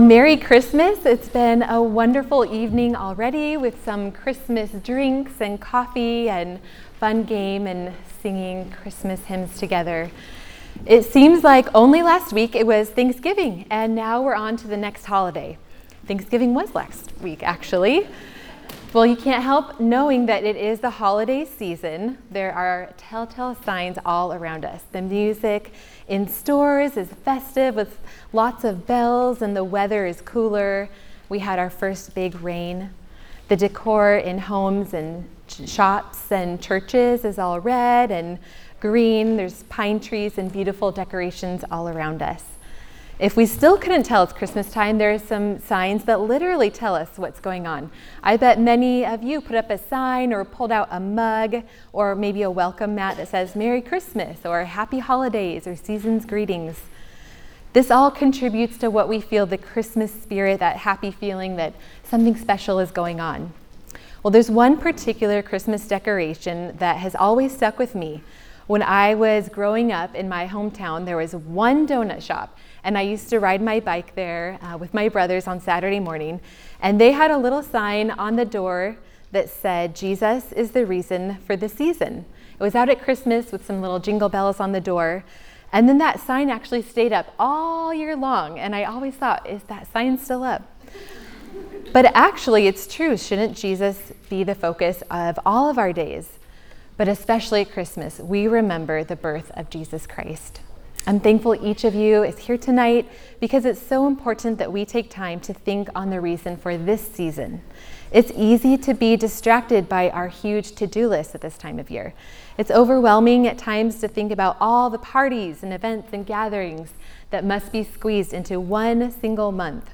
Women's Christmas Coffee (Sermon) - Compass Bible Church Long Beach